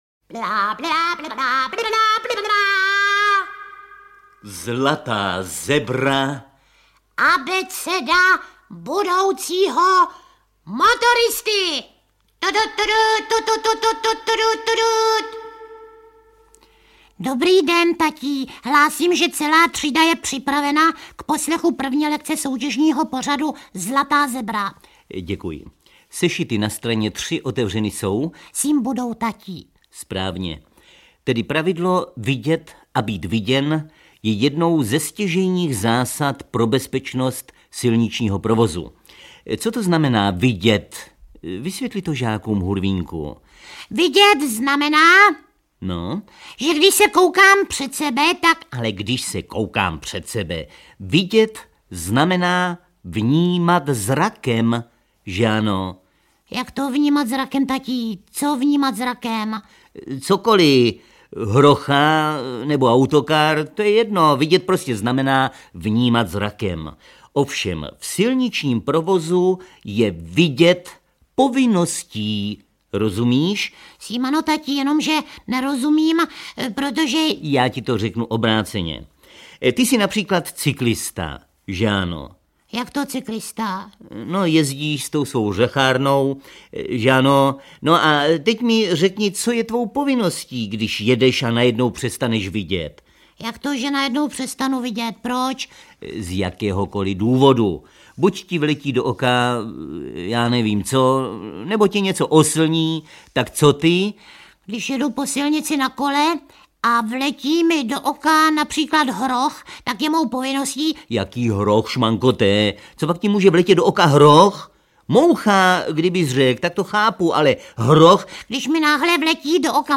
Audio knihaSpejbl a Hurvínek - Zlatá zebra 2
Ukázka z knihy
• InterpretMiloš Kirschner